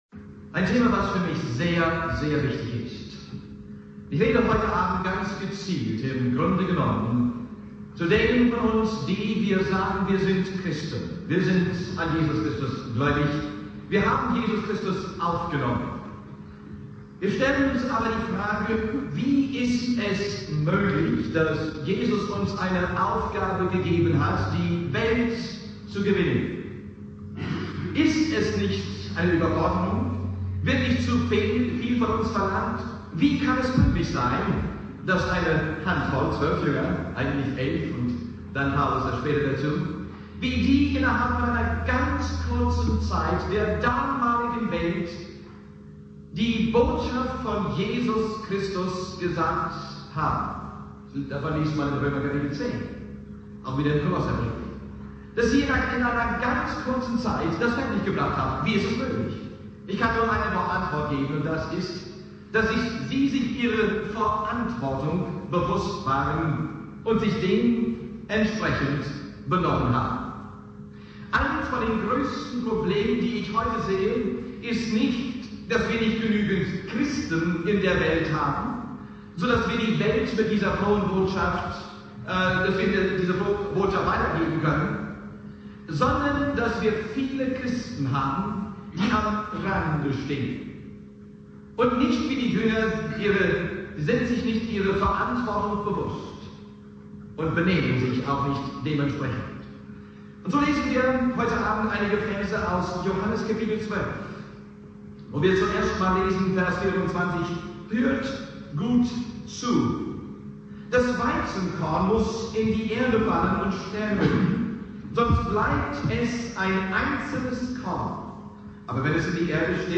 Predigt
später FeG Heusenstamm Thema: Verantwortung als Christ, die Botschaft weiterzugeben - 8. Abend der Evangeliumswoche